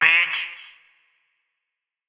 Vox 5 [ bitch ].wav